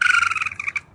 sounds_raccoon_chatter_baby_02.ogg